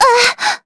Maria_L-Vox_Damage_kr_01.wav